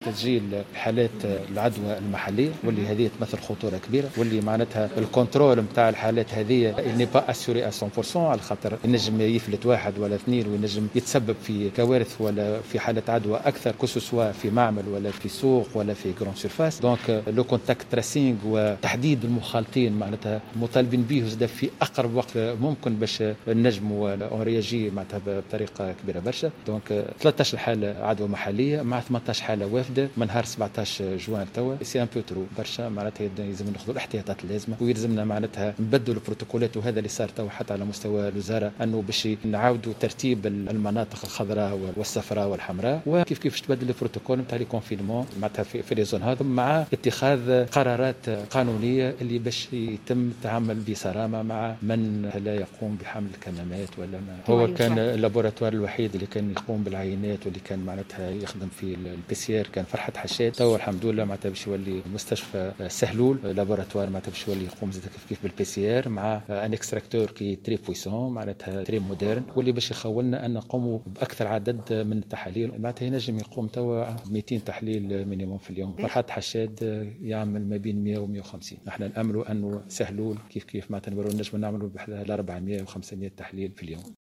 حذر المدير الجهوي للصحة بسوسة محمد الميزوني الغضباني في تصريح للجوهرة "اف ام" مساء اليوم الخميس، من عودة تسجيل إصابات محلية بفيروس كورونا، ما قد يشكل خطورة كبيرة في حالة عدم السيطرة عليها جميعا بنسبة 100%.
وقال الغضباني، في تصريح للجوهرة أف أم، على هامش مجلس جهوي للصحة انعقد مساء اليوم الخميس في مقر ولاية سوسة، إن الجهة سجلت 13 حالة إصابة محلية و18 حالة إصابة وافدة بفيروس كورونا منذ 17 جوان الماضي، وهو رقم وصفه بالكبير جدا، والذي يستدعي إعادة النظر في بروتوكولات الحجر الصحي وتصنيفات الدول في الخانات الخضراء والحمراء.